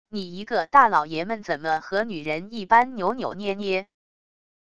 你一个大老爷们怎么和女人一般扭扭捏捏wav音频生成系统WAV Audio Player